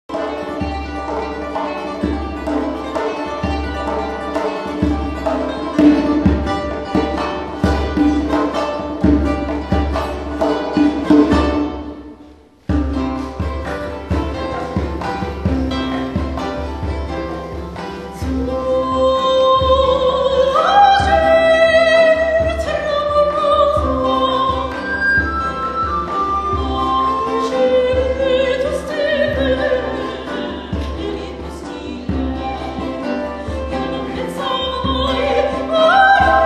Konser'den örnekler
flut, viola da gamba, viele, colascione, tiorbino, chitarrone, barok arp, cornets a bouquin, bendir
hanende, şehrud, ud, ney, tanbur, santur, kanun, rebab, mazhar